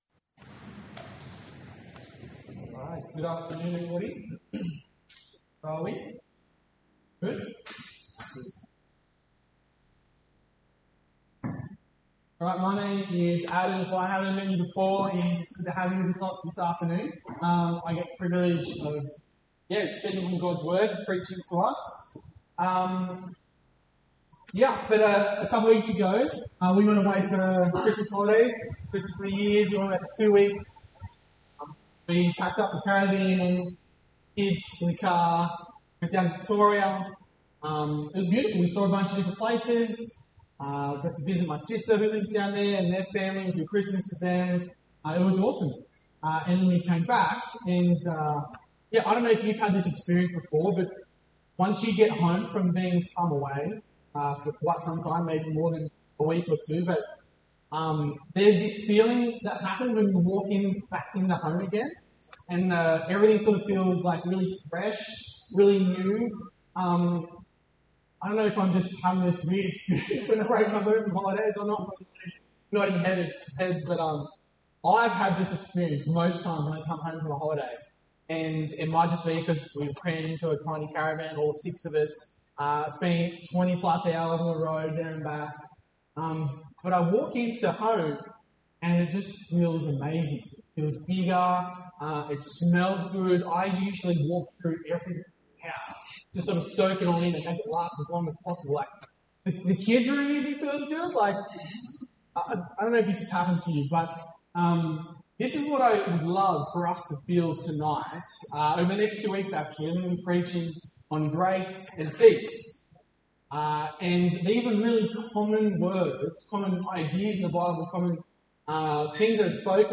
Village Church Home I'm New Who is Jesus Sermons Grace January 18, 2026